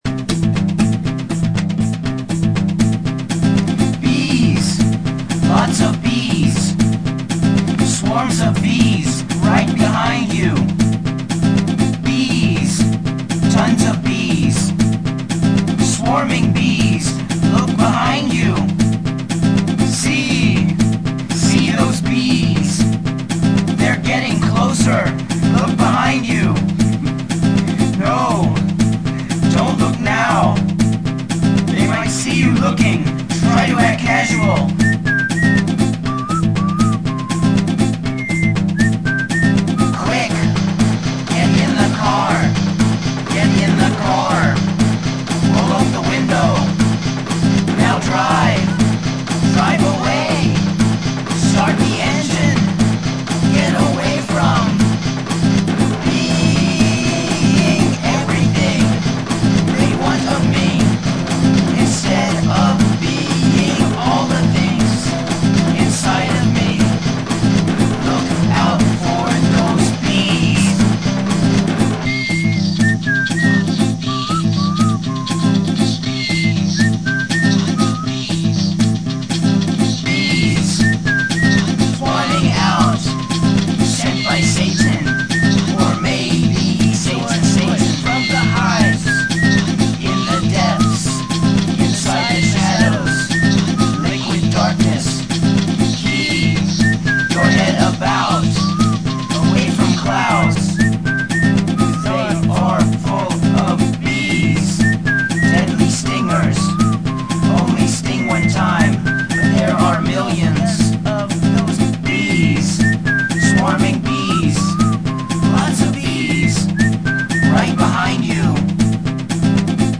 Nashville Nerdrock